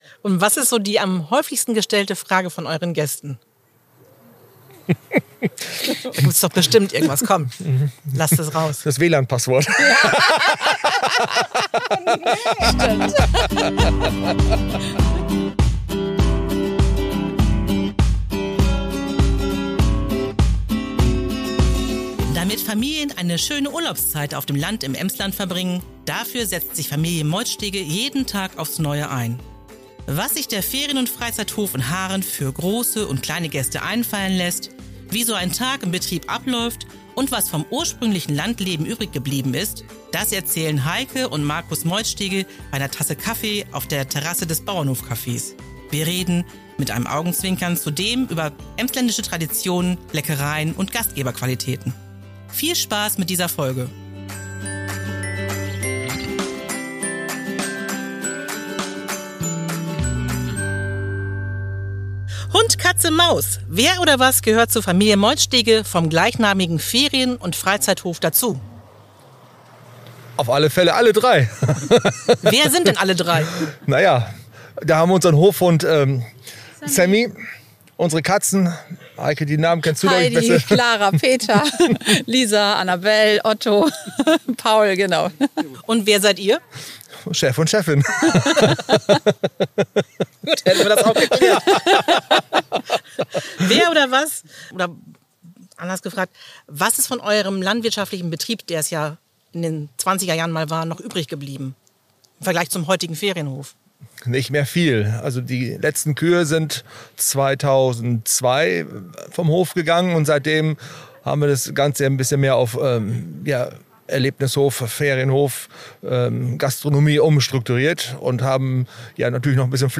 bei einer Tasse Kaffee auf der Terrasse des Bauernhofcafés